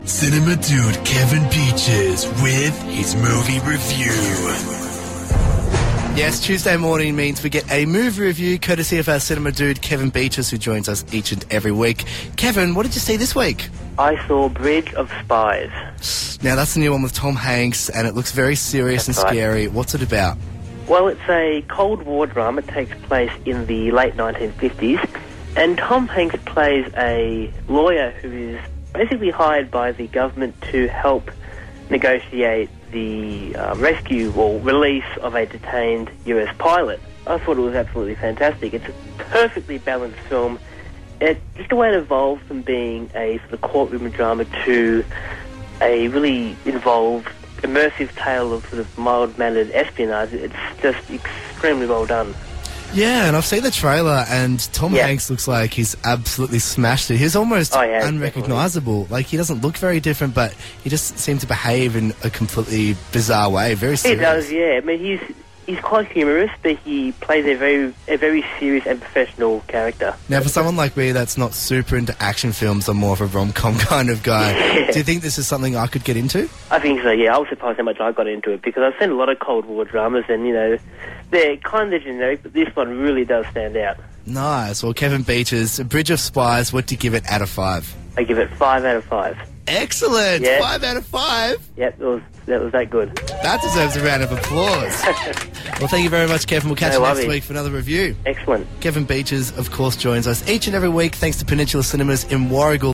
Reviewed on Star FM Gippsland